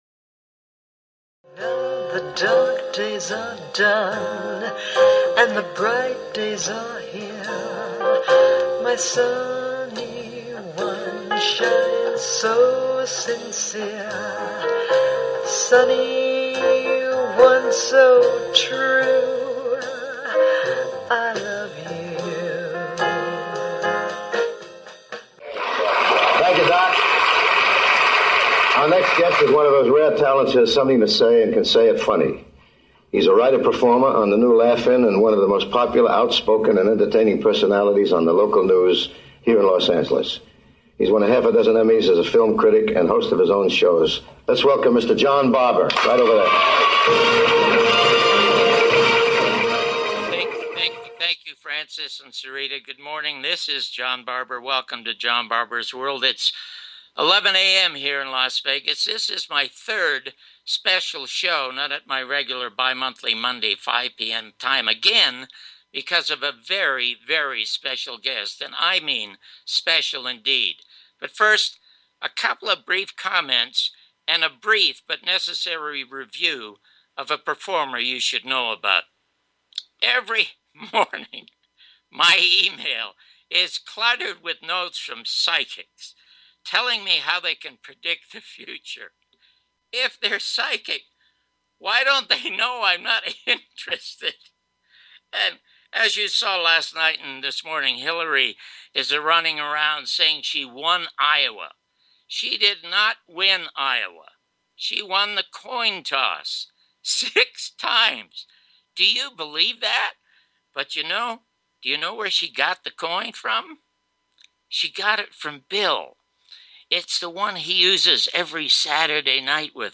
Talk Show Episode
1st Part interview with Ed Asner